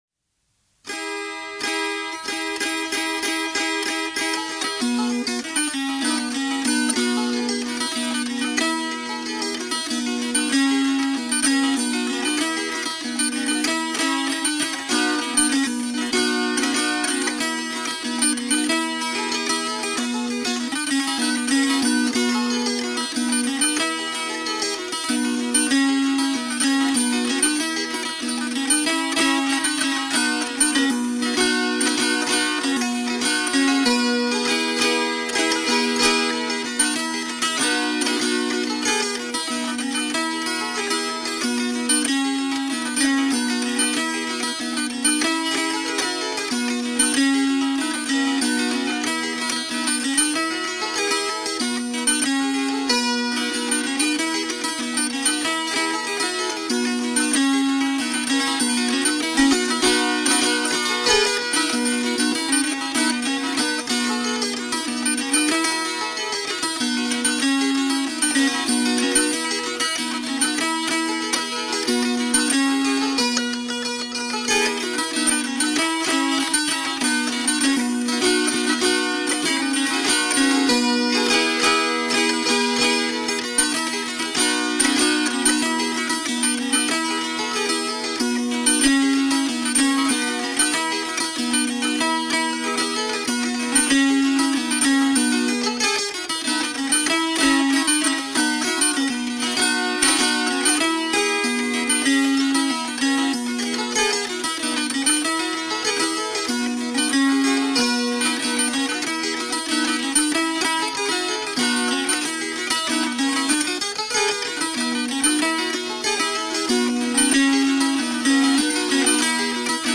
TG   Gusli playing
gusli.mp3